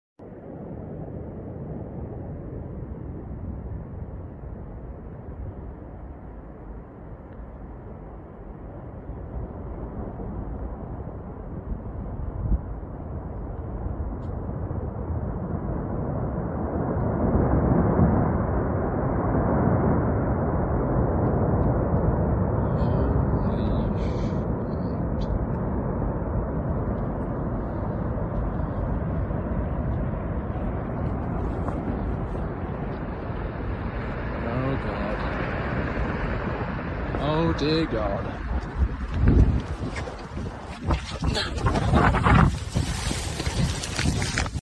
GLACIER OUTBURST FLOOD Clip sound effects free download